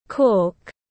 Nút bần tiếng anh gọi là cork, phiên âm tiếng anh đọc là /kɔːrk/.
Cork /kɔːrk/